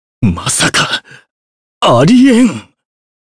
Kain-Vox_Dead_jp.wav